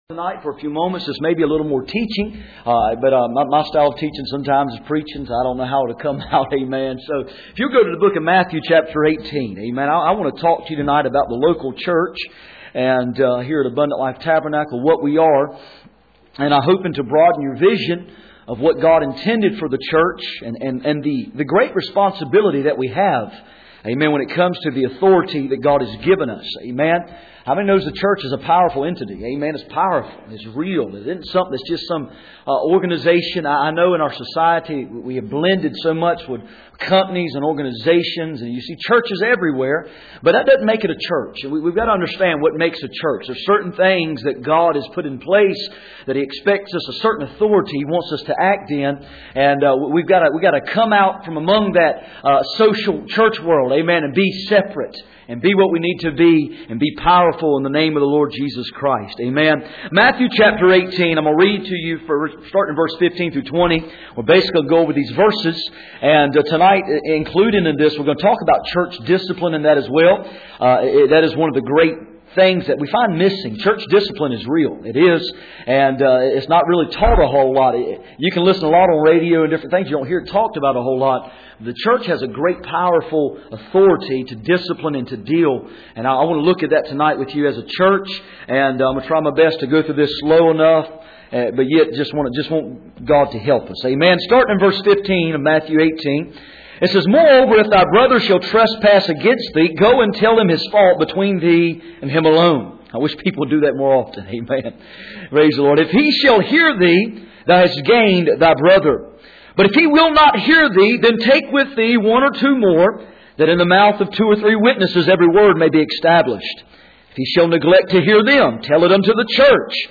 Passage: Matthew 16:15-20 Service Type: Sunday Evening